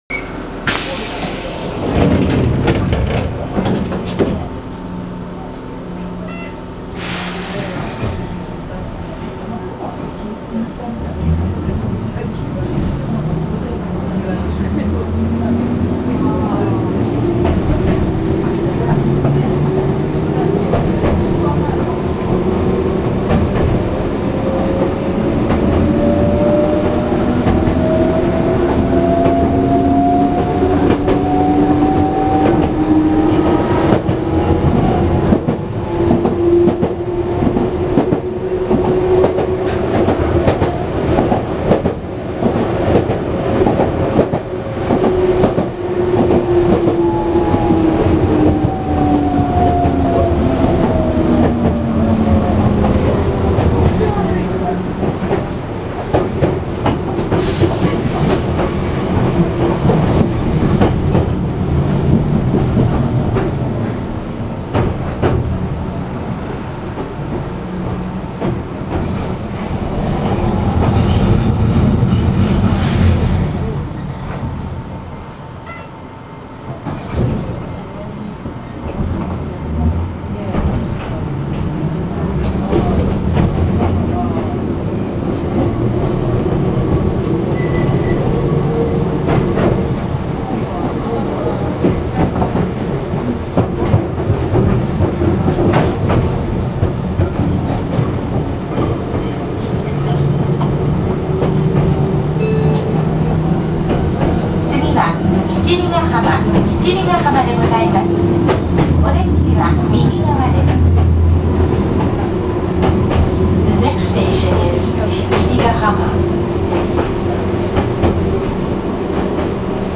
・300形（305編成）走行音
【江ノ島電鉄線】鎌倉高校前〜七里ヶ浜（2分49秒：922KB）
見た目から吊り掛け式を期待してしまいますが走行装置は更新されているのでごく普通のカルダン駆動となっています。車内自動放送も設置されているため、サービス面では他の車両とあまり変わらないのかもしれません。